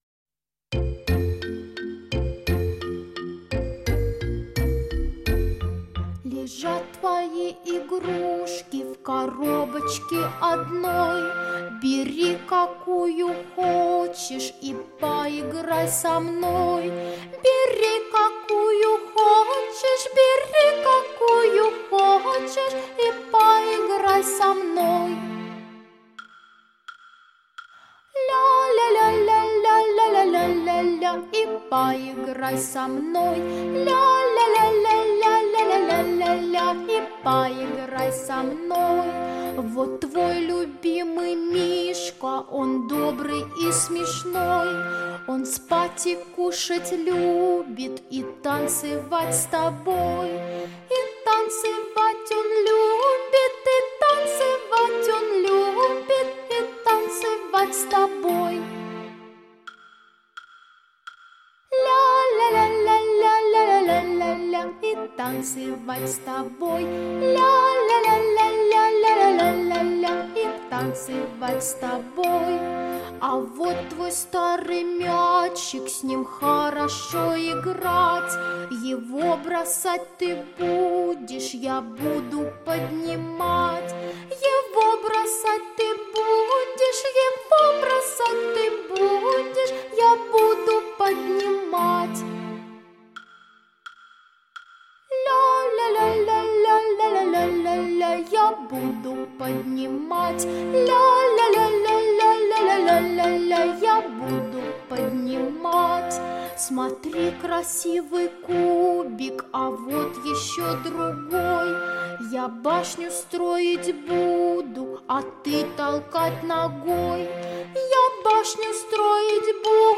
Современные, новые, популярные песни для детей 👶👧